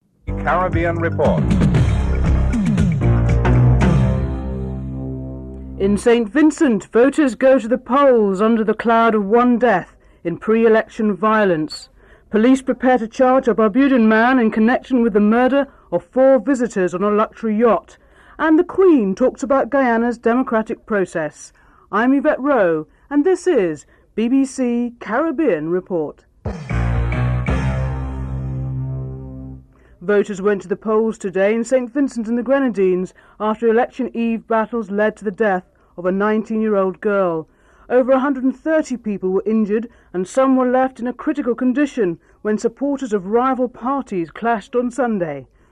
1. Headlines (00:00-00:29)
8. Wrap up and theme music (14:45-14:53)